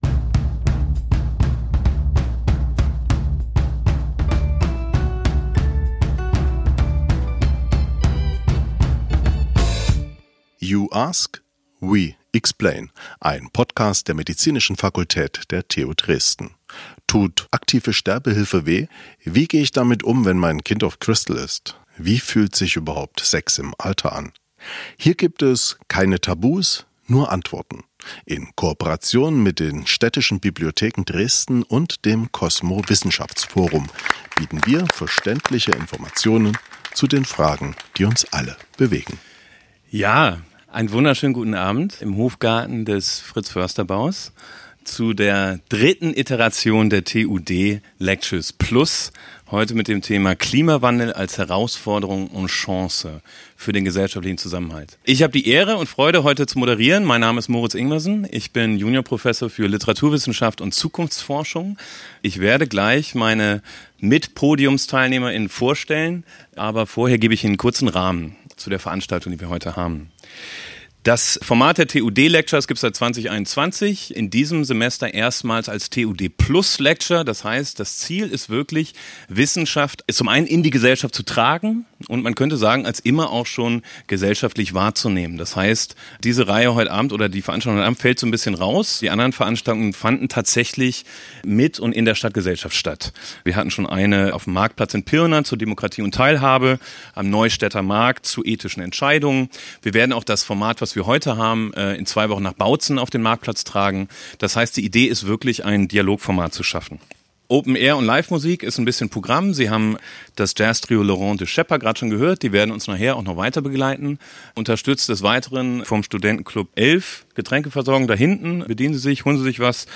Beschreibung vor 1 Jahr In dieser Folge diskutierten wir im Rahmen der TUD Lectures+ über das Thema: Klimawandel als Herausforderung und Chance für den gesellschaftlichen Zusammenhalt. Dazu waren wir auf im Innenhof des Fritz-Förster Baus im Gespräch mit unseren Expert:innen:
Musikalische Begleitung